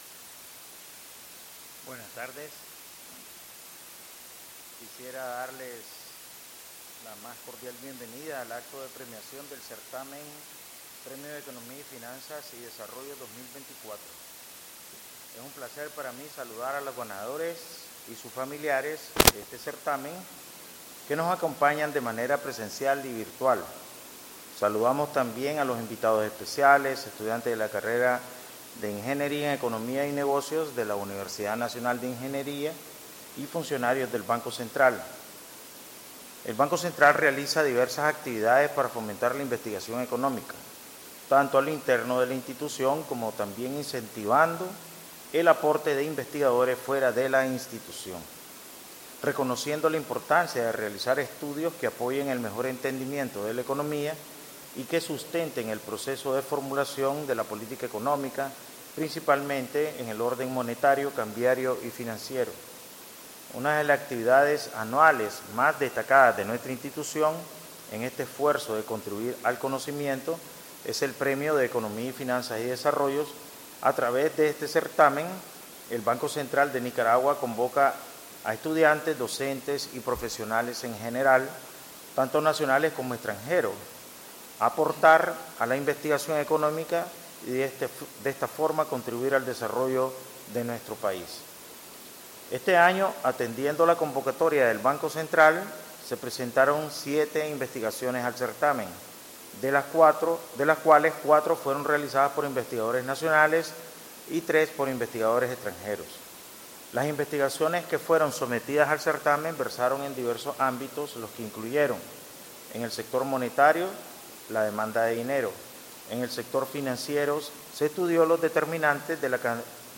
El Presidente del Banco Central de Nicaragua (BCN), Ovidio Reyes Ramírez, hizo entrega de los premios a los ganadores del certamen “Premio de Economía, Finanzas y Desarrollo 2024”, en una ceremonia efectuada el 08 de octubre de 2024, en la Sala Pinacoteca de la Biblioteca Rubén Darío.
Audio: Palabras del presidente del Banco Central de Nicaragua, Ovidio Reyes